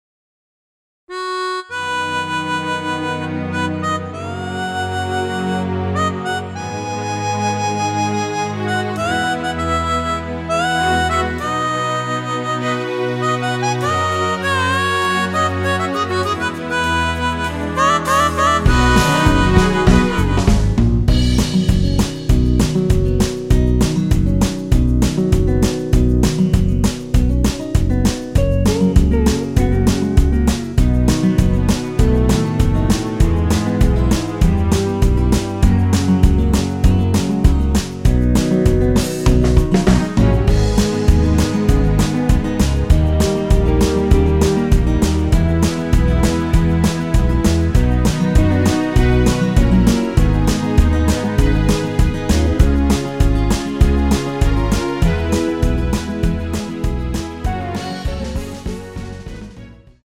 전주가 길어서 8마디로 편곡 하였으며
원키에서(-1)내린 (1절+후렴)으로 진행되는MR입니다.
◈ 곡명 옆 (-1)은 반음 내림, (+1)은 반음 올림 입니다.
앞부분30초, 뒷부분30초씩 편집해서 올려 드리고 있습니다.
중간에 음이 끈어지고 다시 나오는 이유는